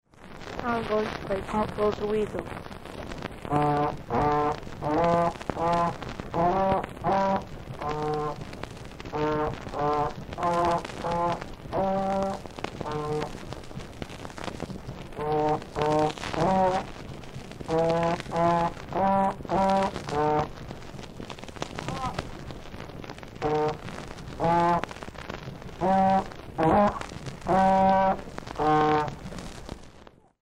Sometime back in late 1959/early 1960 or so, my father brought home a "state-of-the-art" Dictaphone, a device that captured sounds on a thin, green plastic record.
Since the only instrument I played back then was trombone (and pretty lamely, I might add), that is what I chose for my recording debut. So sit back, click the player above, and listen to what can best be described as an ailing, flatulent duck; me, in my first audio recording ever, "performing" Pop Goes The Weasel on the trombone.